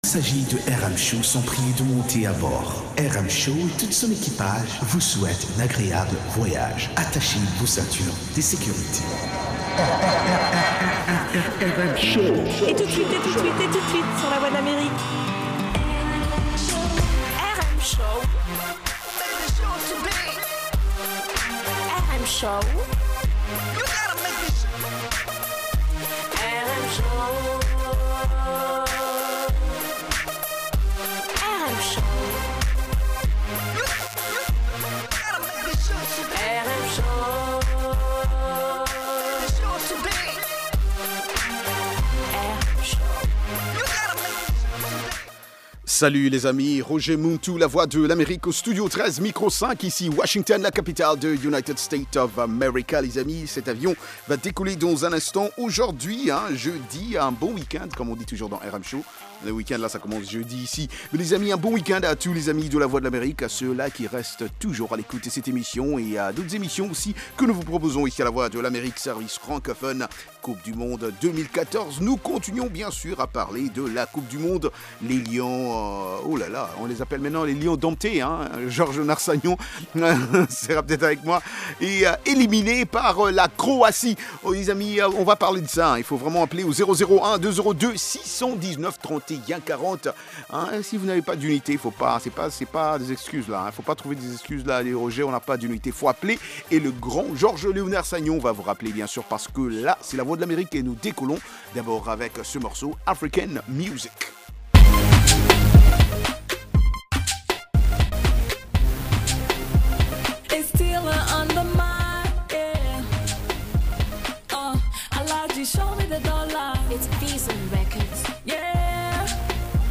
propose notamment d'écouter de la musique africaine, des articles sur l'actualité Afro Music, des reportages et interviews sur des événements et spectacles africains aux USA ou en Afrique.